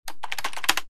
Keyboard5.wav